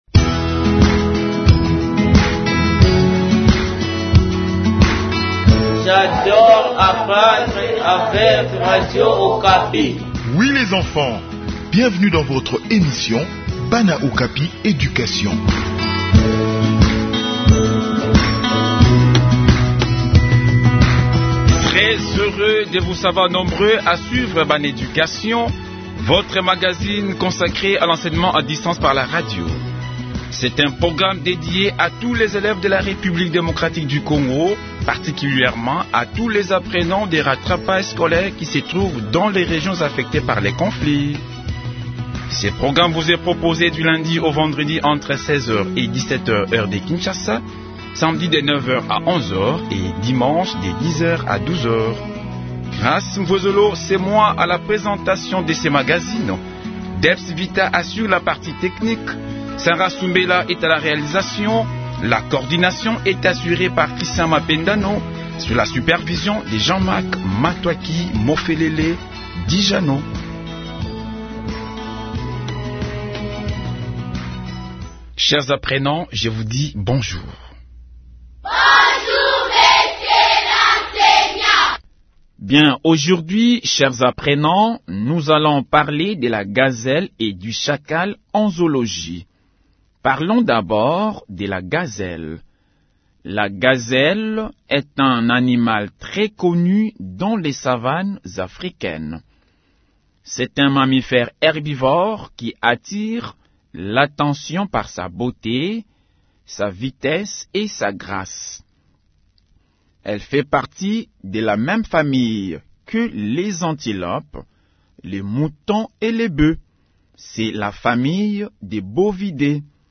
Enseignement à distance : leçon de zoologie sur la gazelle et le chacal